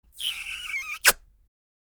public / sounds / emoji / kiss.mp3